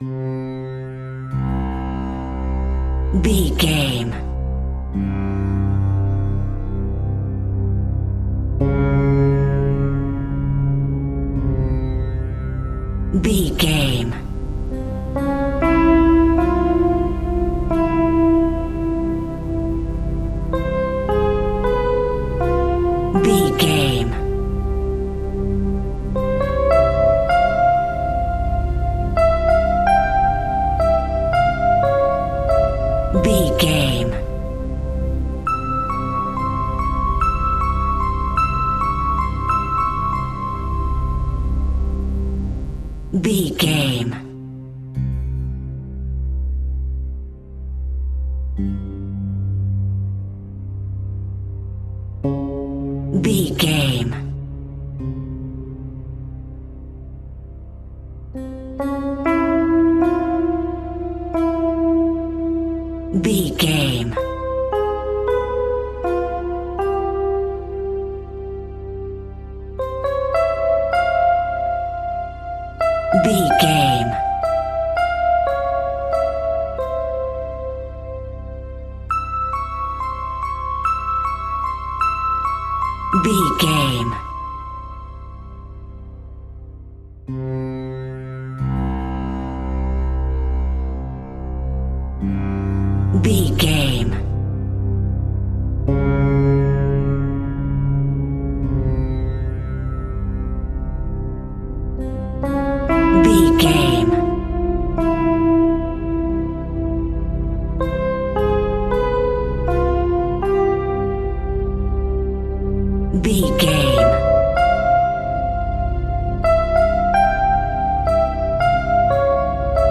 Phrygian
Slow
ambient
indian sitar